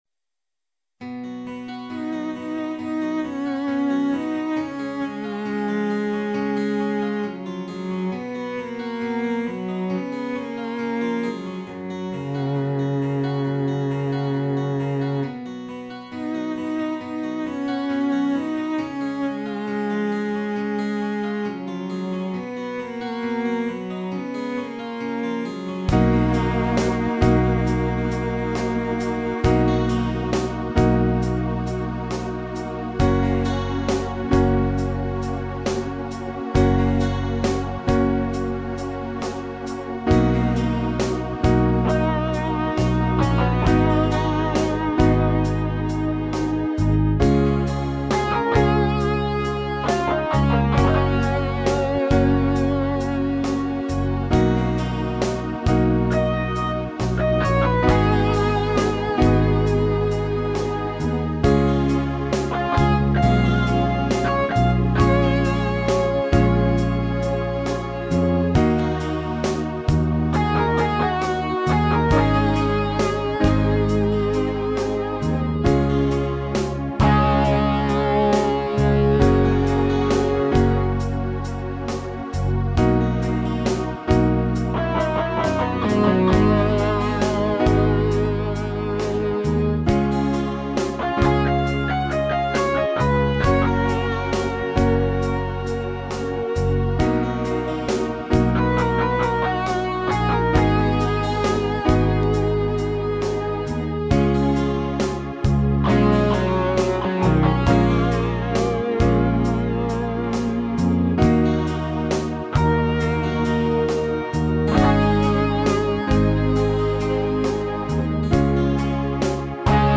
MIDI